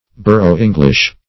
Search Result for " borough-english" : The Collaborative International Dictionary of English v.0.48: Borough-English \Bor"ough-Eng"lish\, n. (Eng. Law) A custom, as in some ancient boroughs, by which lands and tenements descend to the youngest son, instead of the eldest; or, if the owner have no issue, to the youngest brother.